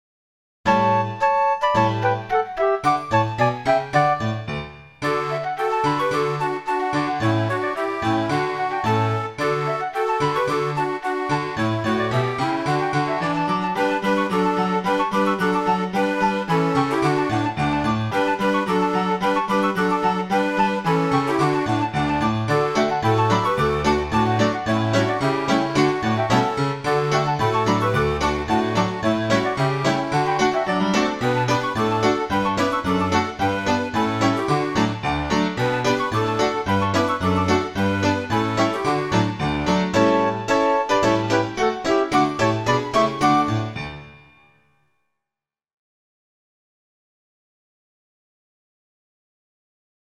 Piesne pre deti a rodinu
jablcko-cervene-podklad.mp3